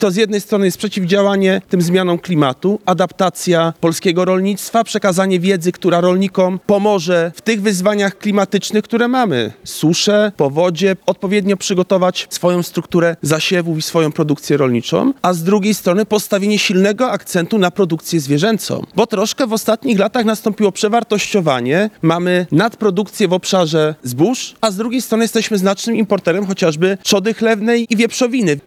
– Pokazujemy także, jakie wyzwania stoją przed polskim rolnictwem – mówi podsekretarz stanu w Ministerstwie Rolnictwa i Rozwoju Wsi, Adam Nowak.
Kongres Nauk Rolniczych trwa w Puławskim Ośrodku Kultury.
Adam-Nowak-kongres-rolniczy.mp3